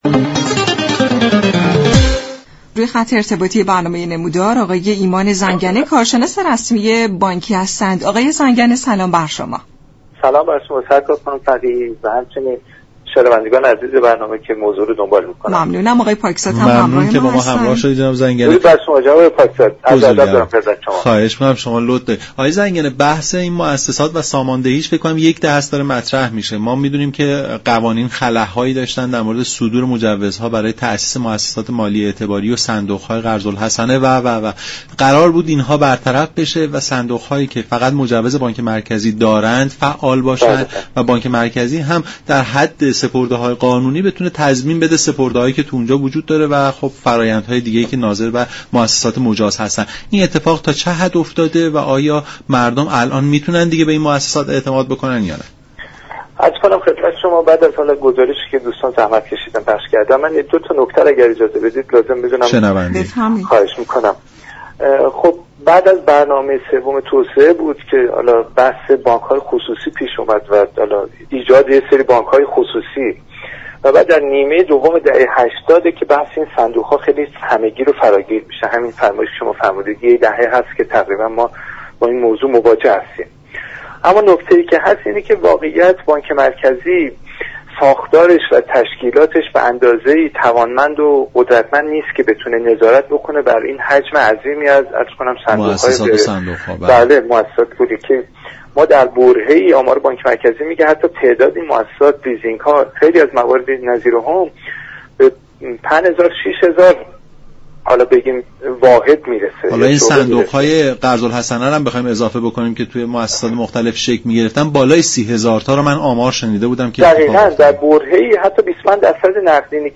كارشناس امور بانكی در گفت و گو با رادیو ایران